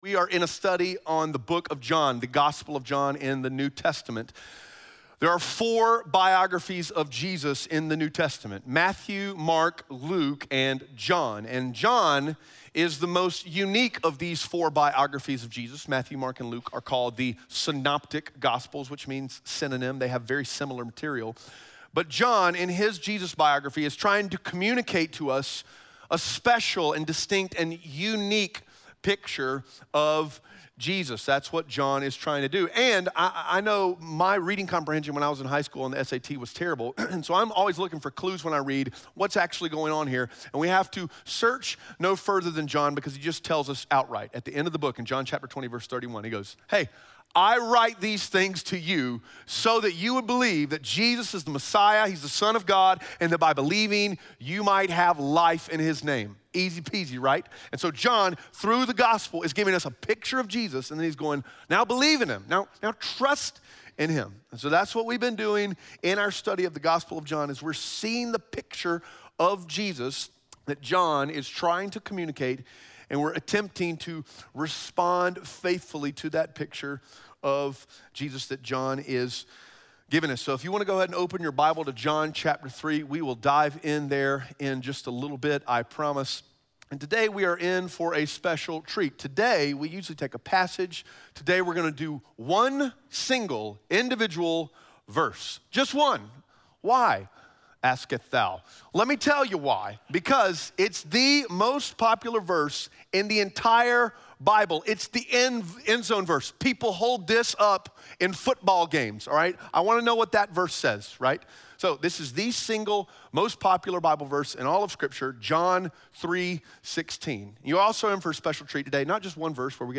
John 3:16 Audio Sermon Notes (PDF) Ask a Question Scripture: John 3:16 Love might be one of the most important realities in the universe.